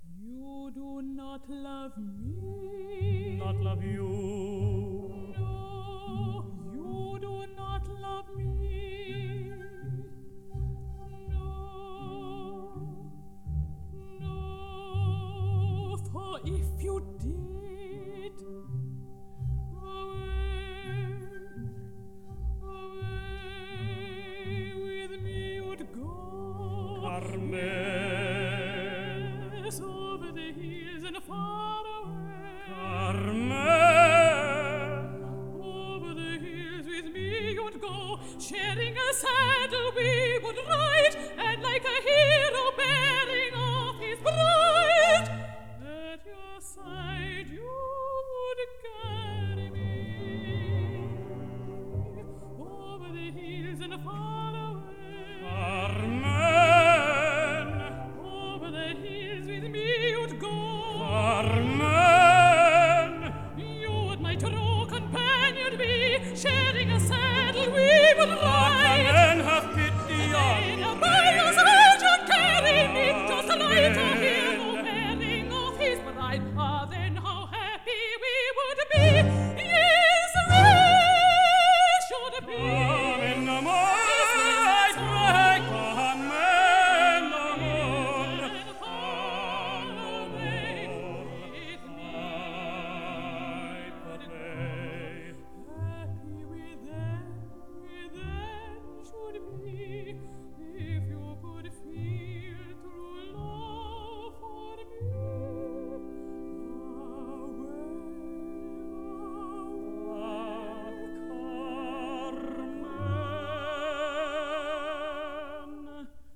sopranos
mezzo-soprano
tenor
bass